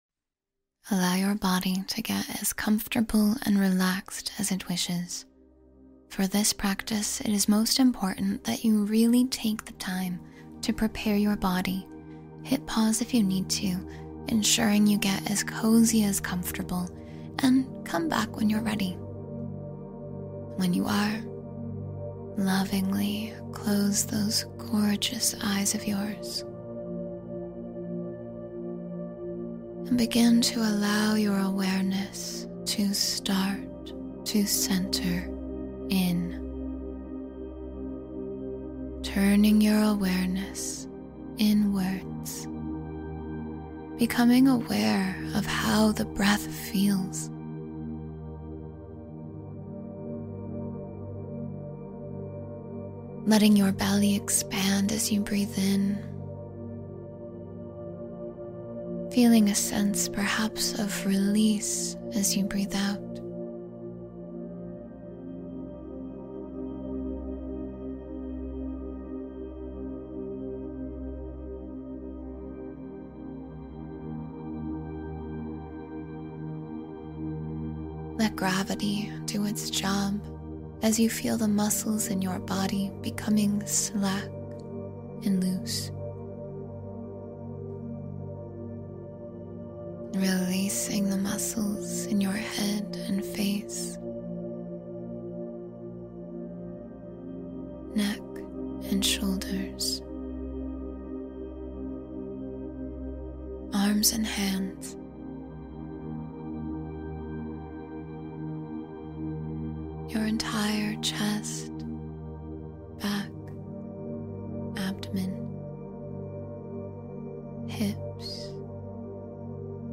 Center Yourself and Find Deep Balance — Meditation for Mental and Emotional Stability